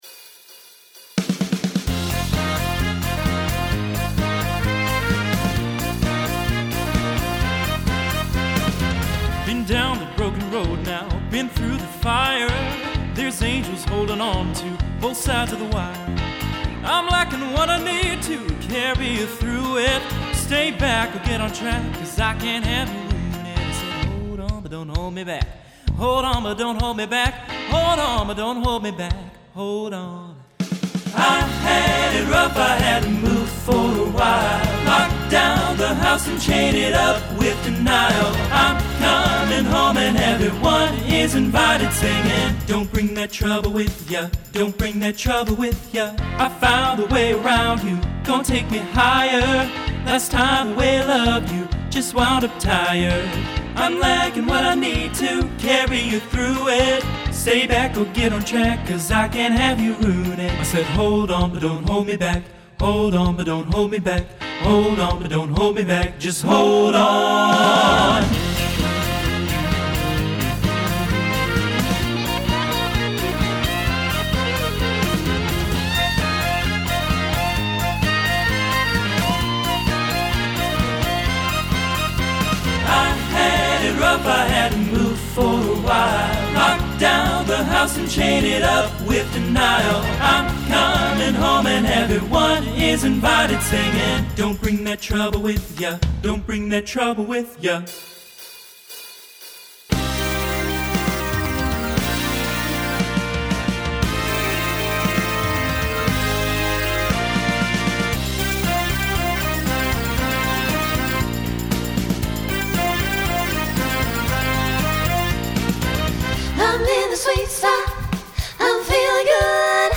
TTB/SSA/SATB
Genre Rock Instrumental combo
Transition Voicing Mixed